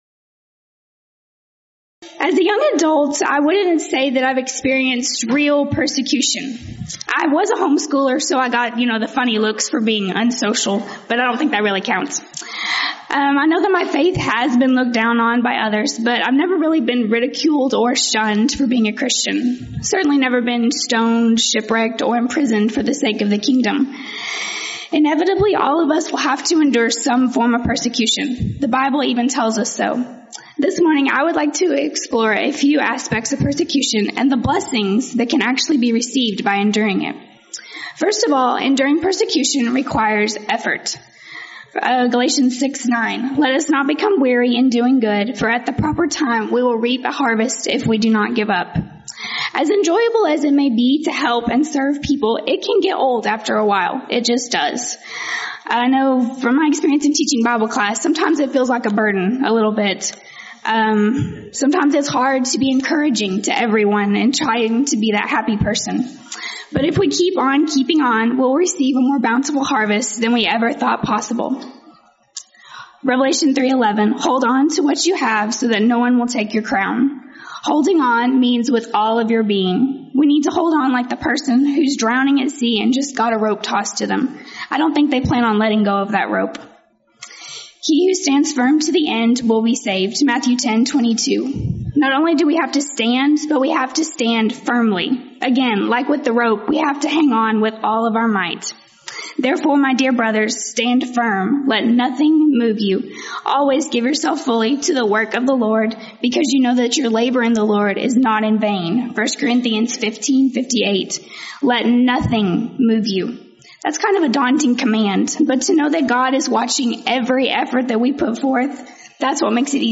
Title: Friday Morning Devotional
Event: 5th Annual Texas Ladies In Christ Retreat